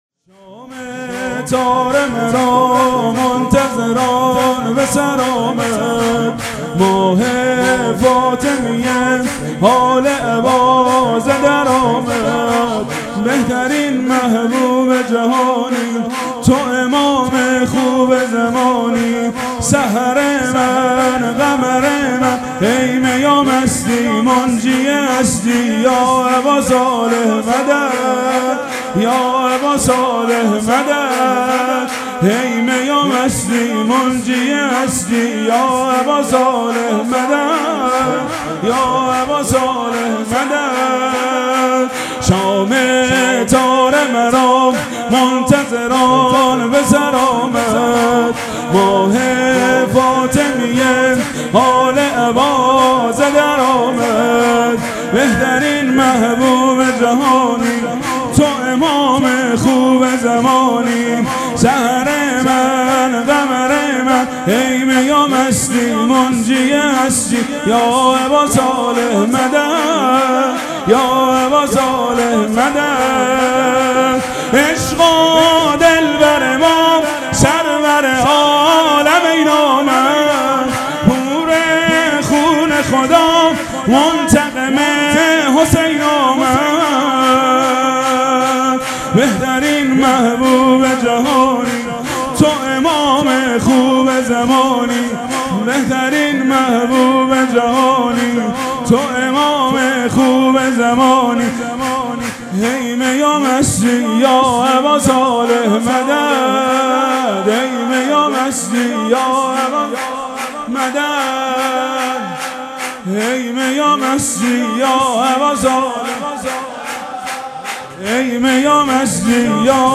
مراسم شب ولادت حضرت صاحب الزمان(عج)
شور
مداح
جشن نیمه شعبان